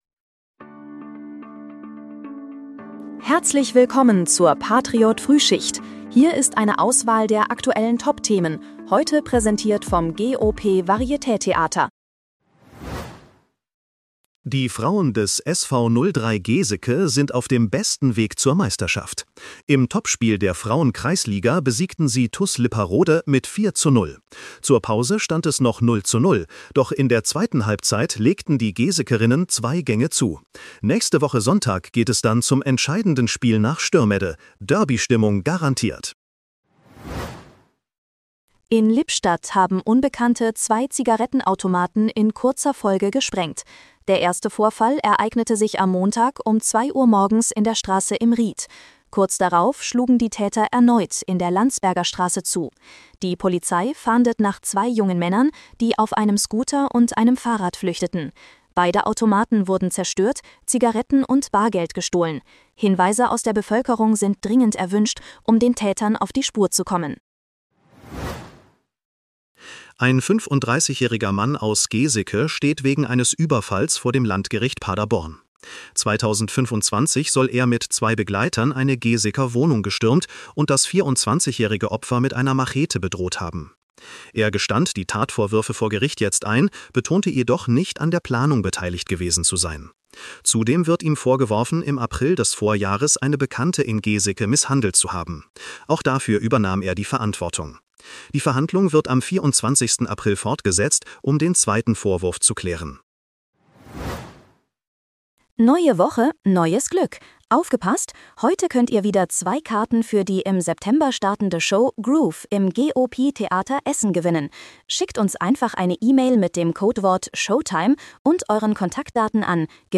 Dein morgendliches News-Update